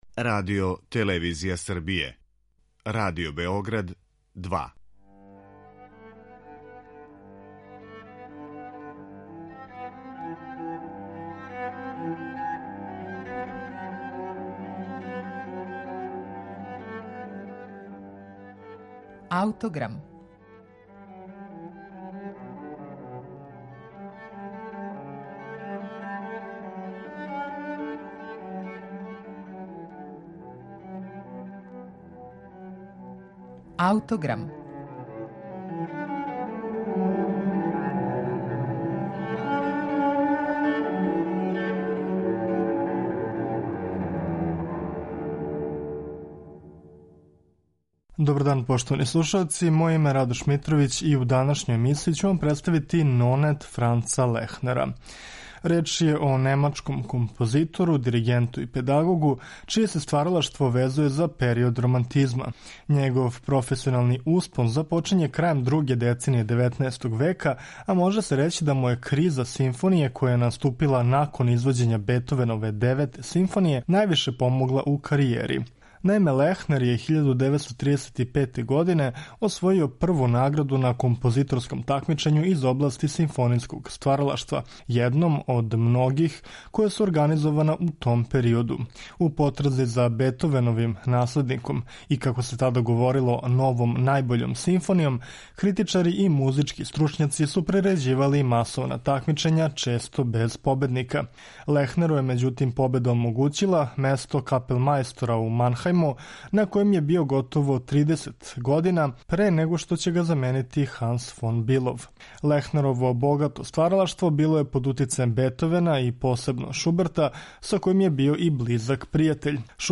Међу њима издвајају се оргуљске сонате, гудачки квартет и Нонет. Управо ћемо Лахнеров Нонет представити у емисији Аутограм , у извођењу Ансамбла Беч-Берлин.